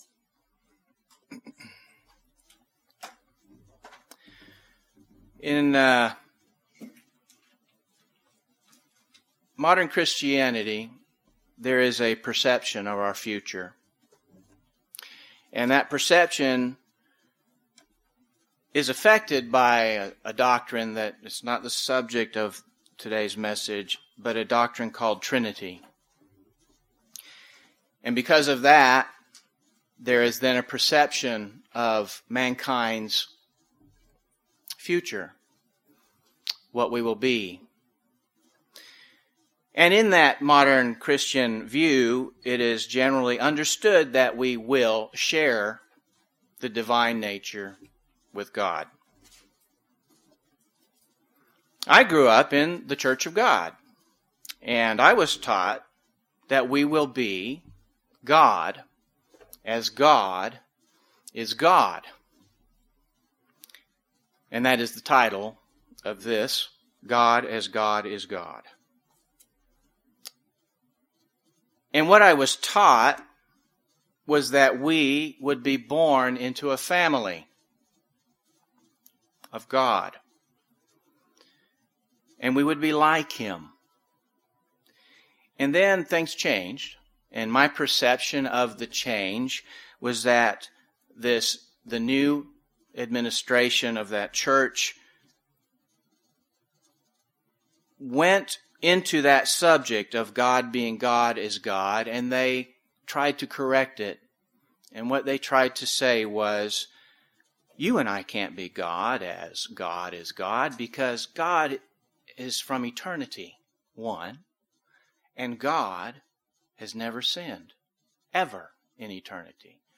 UCG Sermon God the Father Jesus Christ the Word Son of God God's Holy Spirit Trinity Notes PRESENTER'S NOTES 1.